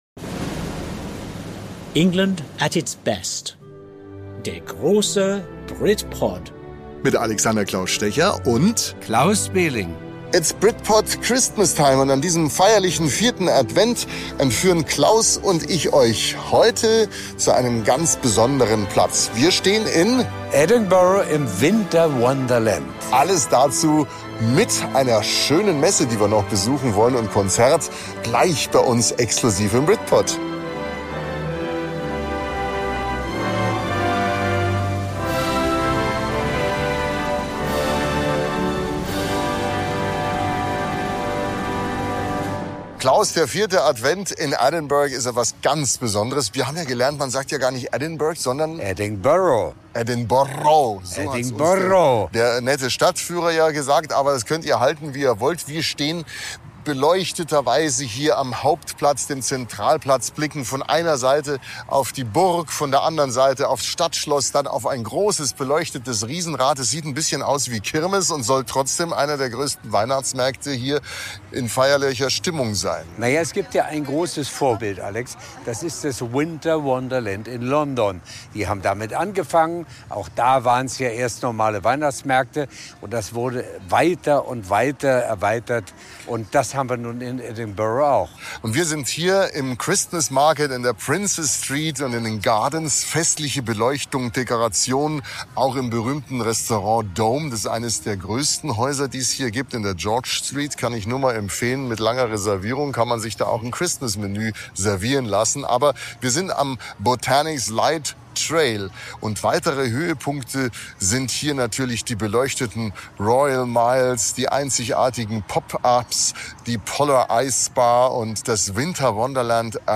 Weihnachtsmusik weht herüber, vermischt sich mit Stimmen und Gelächter.
Ein absolutes Highlight ist deswegen auch das Adventskonzert der University of Edinburgh in der St. Giles Cathedral. Getragen von der besonderen Akustik und der Atmosphäre des vierten Advents ist dieses Konzert exklusiv in dieser Podcast-Folge zu hören - Weihnachtsstimmung, die man nicht nur hört, sondern spürt.